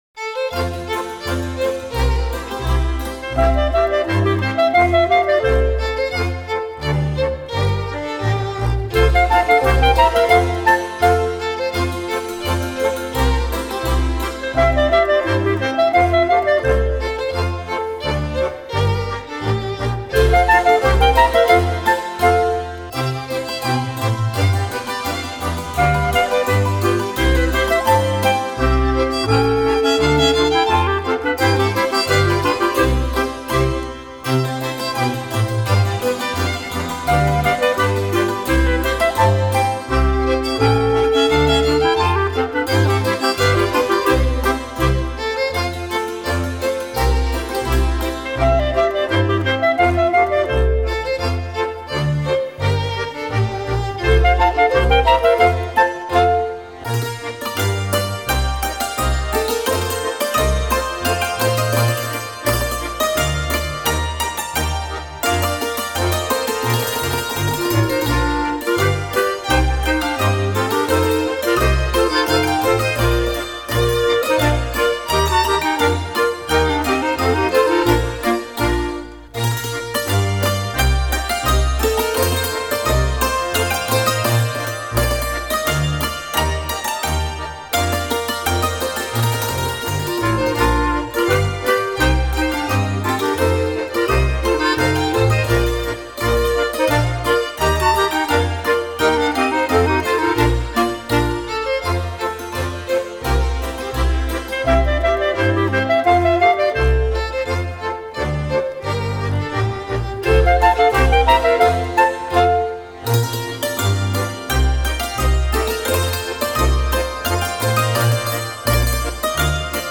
Bim Heida, Polka